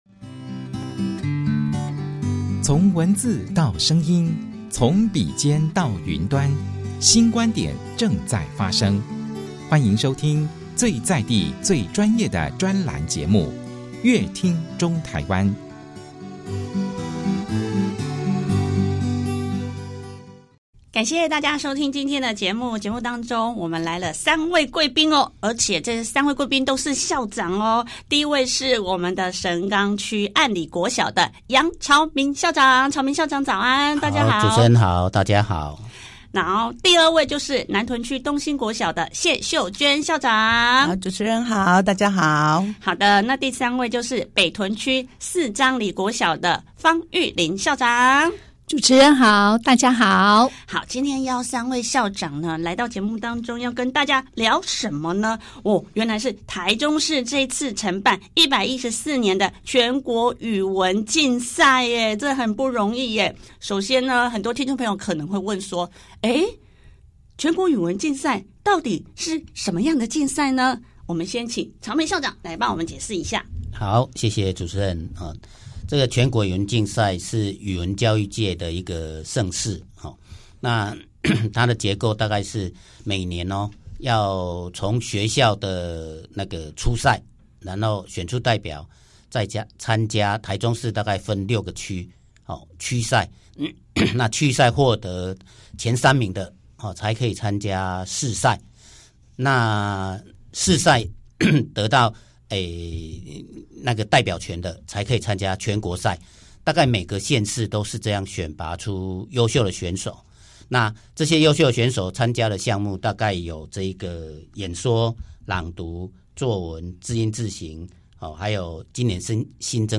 而全國語文競賽舉辦的宗旨為何? 114年全國語文競賽由臺中市承辦，臺中市政府教育局做了那些規劃？想知道更多精彩的訪談內容，請鎖定本集節目。